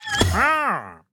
Minecraft Version Minecraft Version 25w18a Latest Release | Latest Snapshot 25w18a / assets / minecraft / sounds / mob / wandering_trader / reappeared2.ogg Compare With Compare With Latest Release | Latest Snapshot
reappeared2.ogg